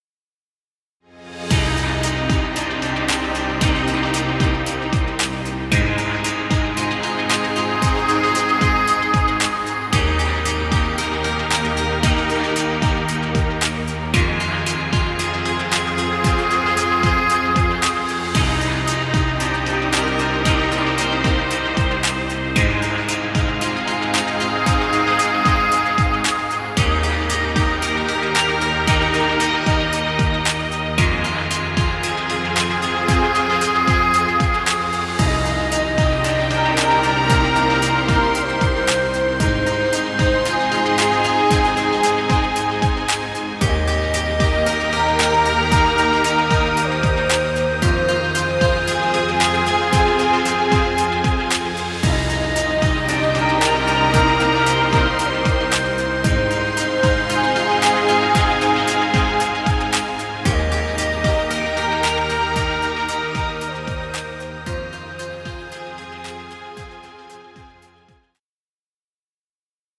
Chillout music.